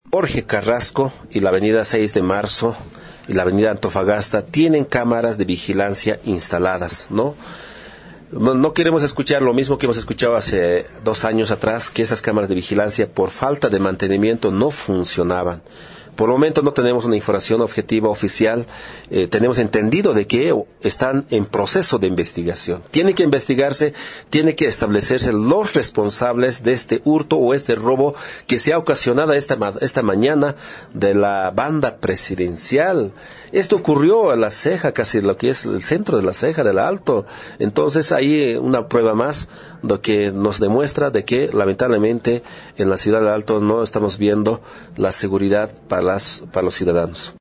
Finalmente el concejal del MAS Juanito Angulo manifestó que no hay seguridad en la ciudad de El Alto y esperan que las cámaras instaladas en el sector del robo estén en funcionamiento.
Juanito-Angulo-medalla-presidencial.mp3